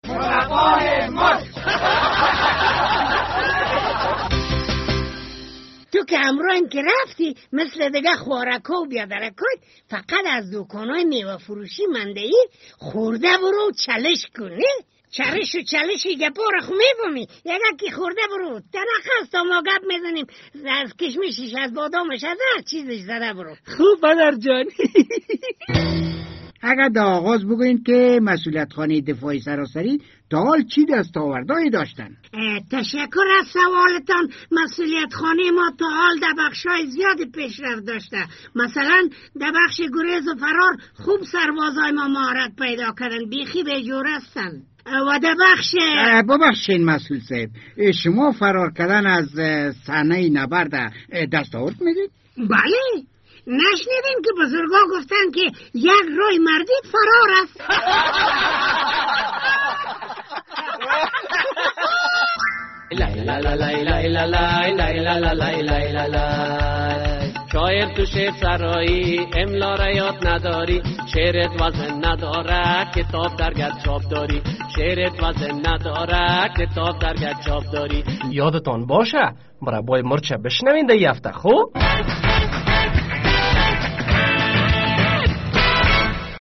ده بخش دوم برنامه مصاحبه ره میشنویم که خبرنگار مربای مرچ با مسئول صاحب یکی از مسئولیت خانه‌ها انجام داده‌است.